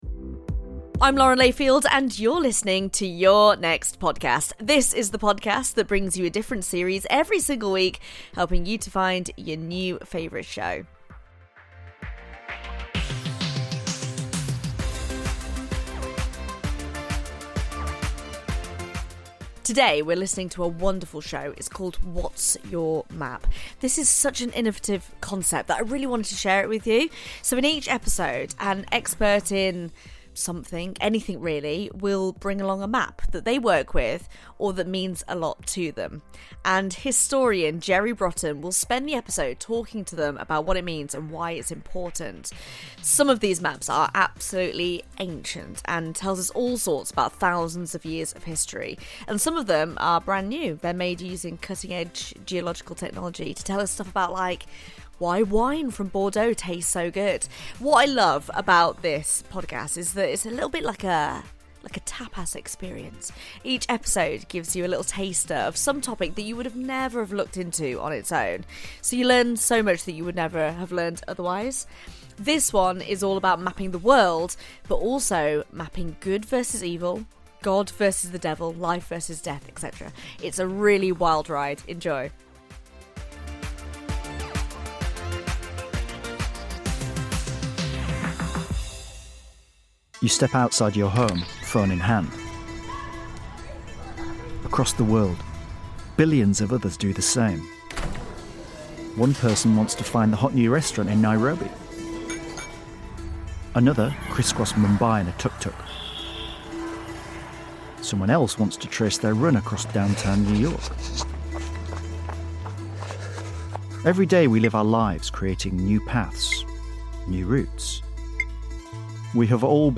Lauren Layfield introduces What's Your Map? on the series recommendation show Your Next Podcast.